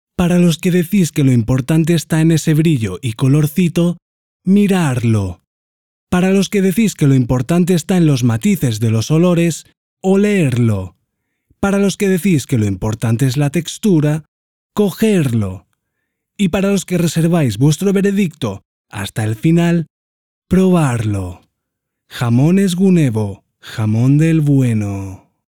Tenor máxima profesionalidad
kastilisch
Sprechprobe: Werbung (Muttersprache):